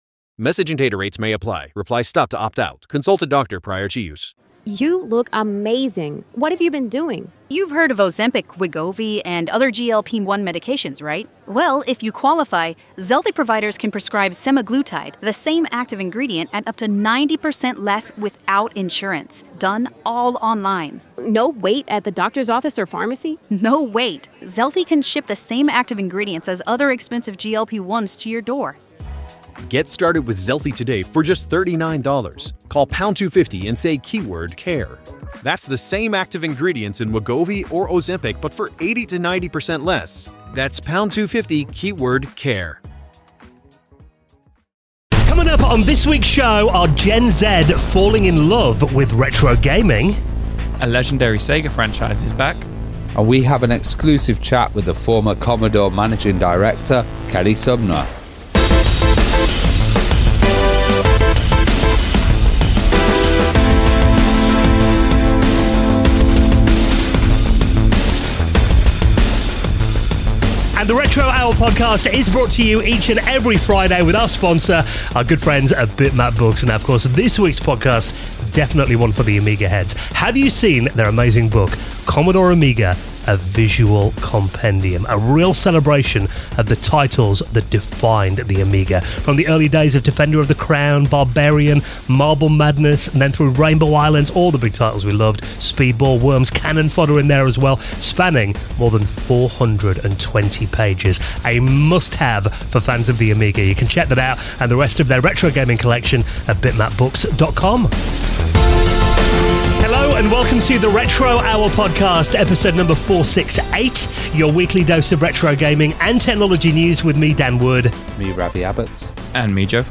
In the first part of our epic two part interview